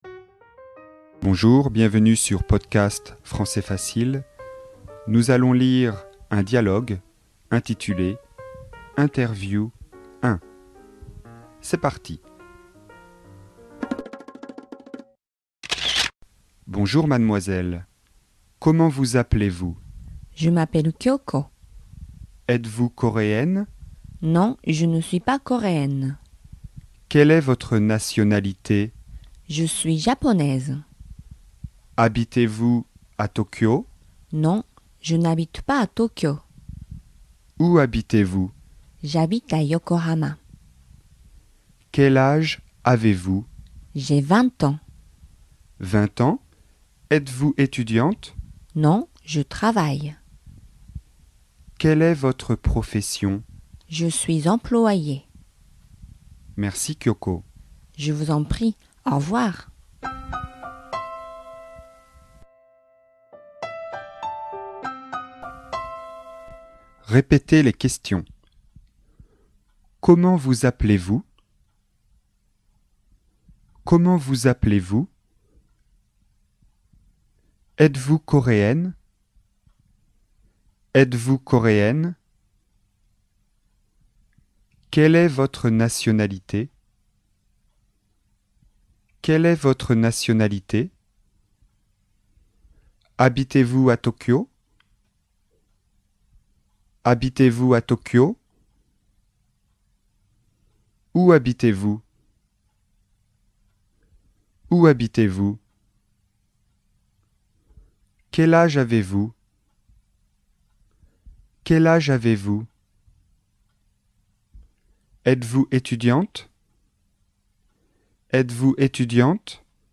Interview
Dialogue pour les premiers pas en français, niveau débutant (A1), sur le thème "parler de soi".